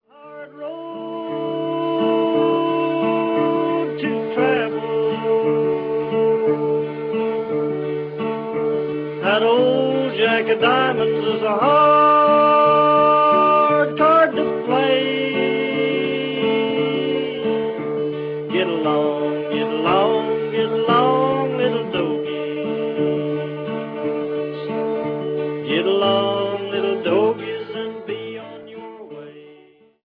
Recorded in New York between 1944 and 1949.